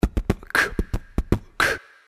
Vocals Short Sounds » Beatbox Boom Tsi Ke Ka
描述：A vocal beatbox rhytmn. Recorded with Edirol R1 Sennheiser ME66.
标签： drums beat rhythm drumming beatbox vocal beatboxing beatbox
声道立体声